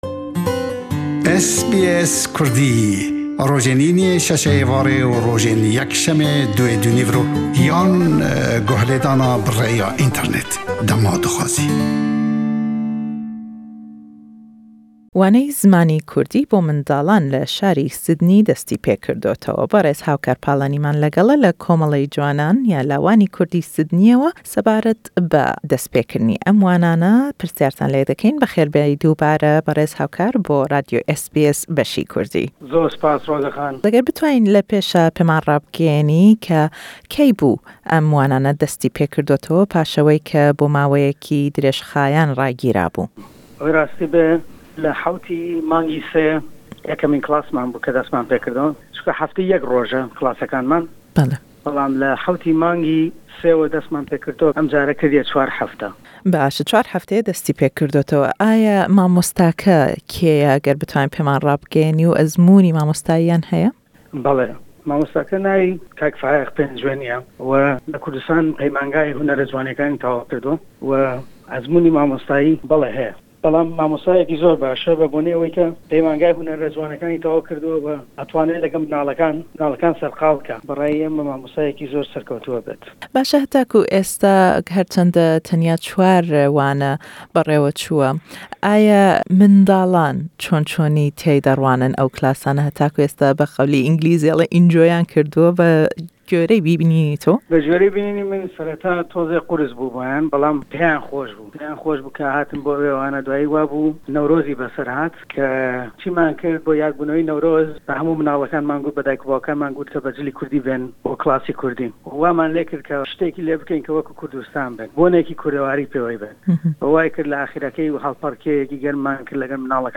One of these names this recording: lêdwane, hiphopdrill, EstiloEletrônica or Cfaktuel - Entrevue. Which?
lêdwane